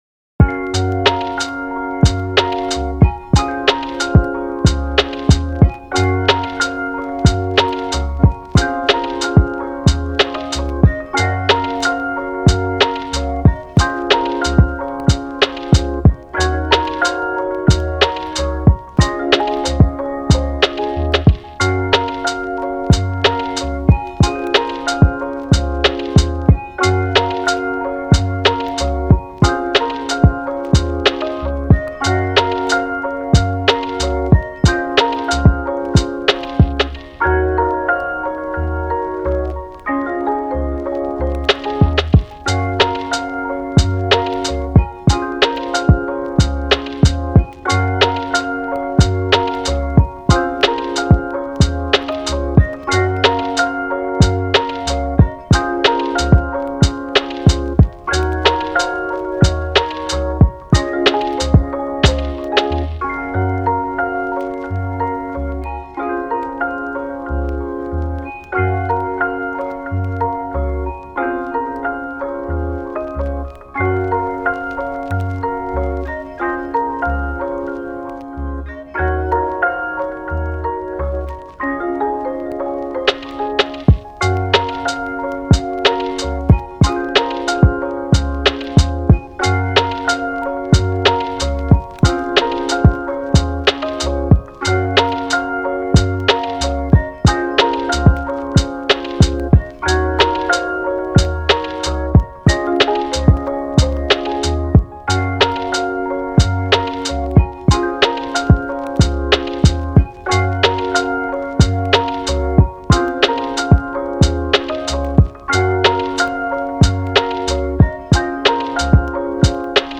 チル・穏やか フリーBGM